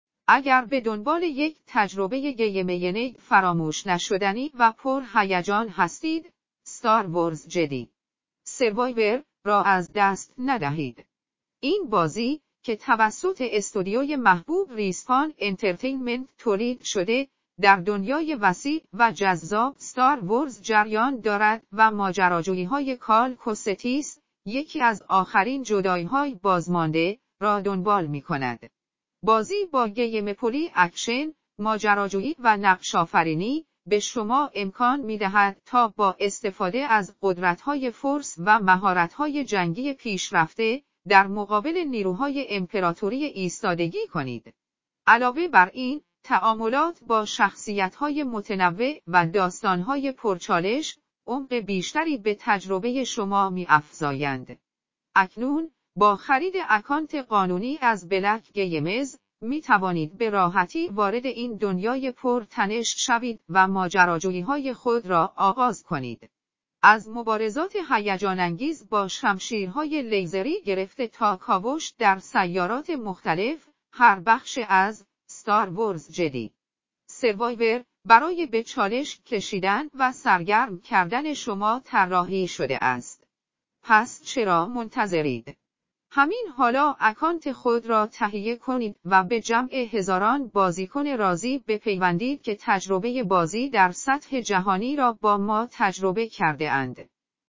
شما می توانید در ادامه با گوش دادن وویس زیر با خلاصه ای از داستان بازی آشنا شوید: